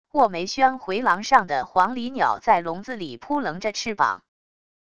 卧梅轩回廊上的黄鹂鸟在笼子里扑棱着翅膀wav音频